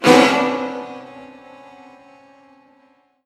Sound Buttons: Sound Buttons View : Violon Screamer
violon-screamer.mp3